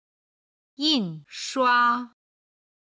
今日の振り返り！中国語発声
01-yinshua.mp3